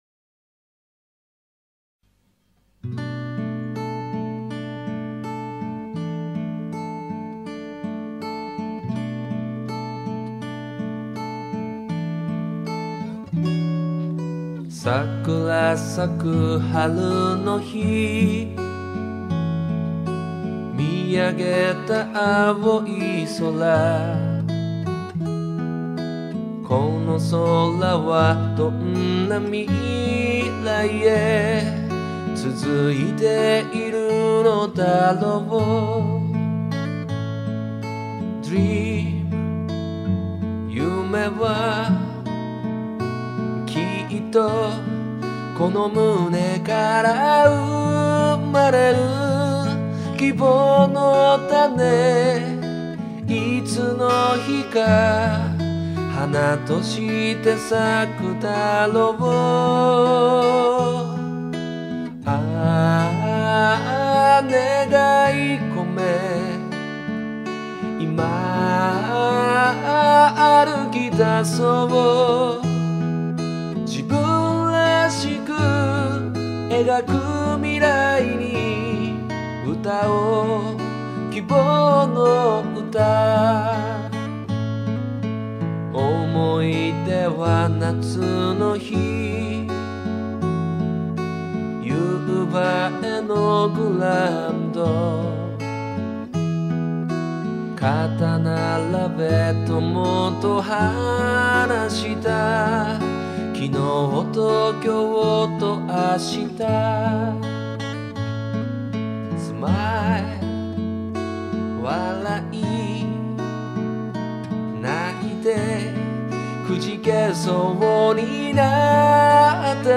作曲者本人による歌はこちらをクリック